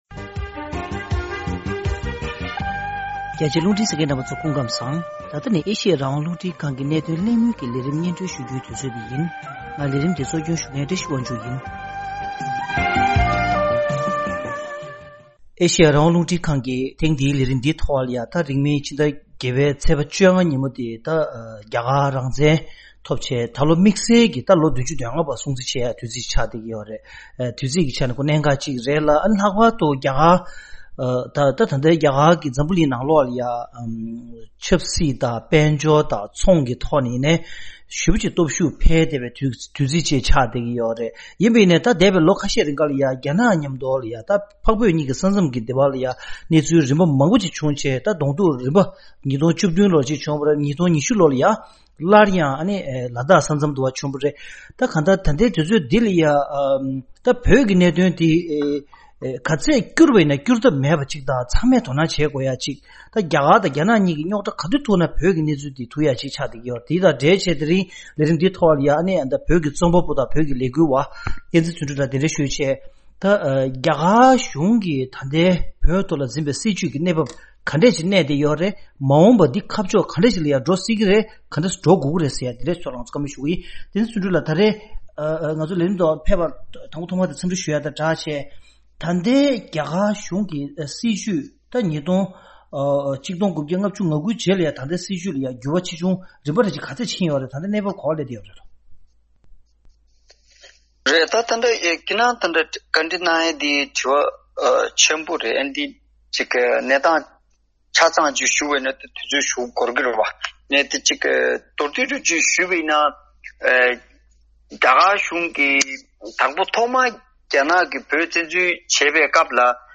གནད་དོན་གླེང་མོལ་གྱི་ལས་རིམ་ནང་།
མདོ་འཛིན་པ་གནང་སྟེ་གླེང་མོལ་ཞུས་པ་འདི་གསན་རོགས་གནང་།